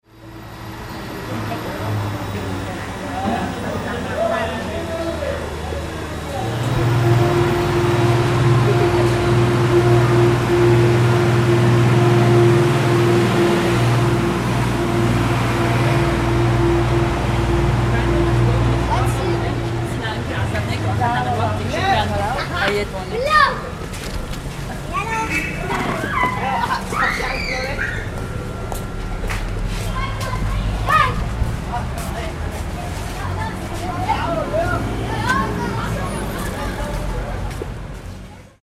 syriast-ambient_market.mp3